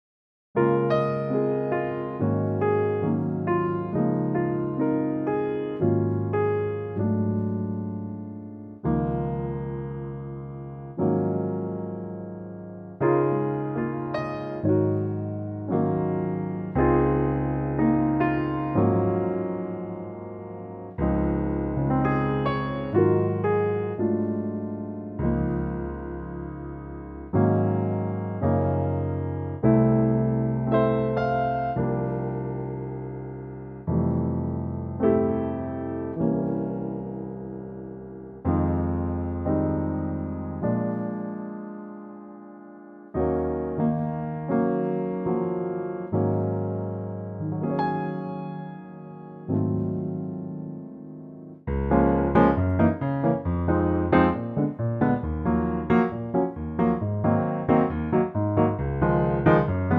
Db Piano Latin
key - Db - vocal range - Bb to Eb
Wonderful piano only arrangement
that goes into a beguine latin feel.